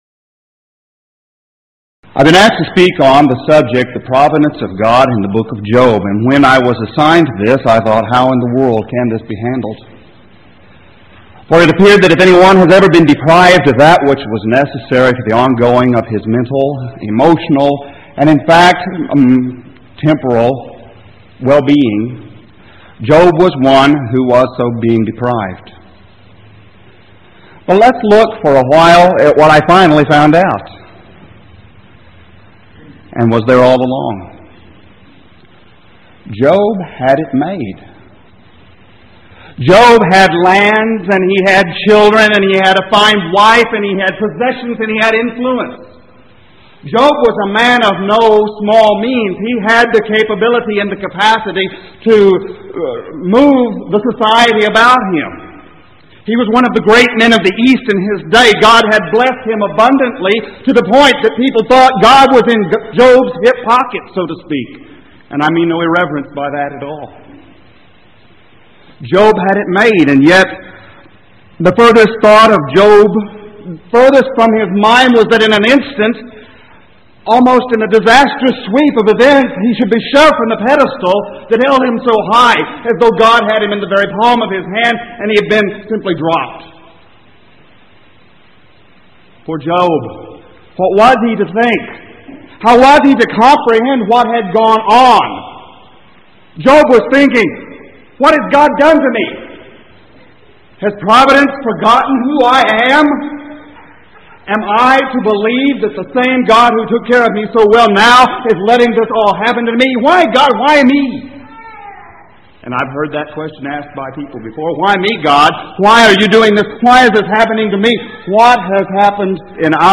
Series: Power Lectures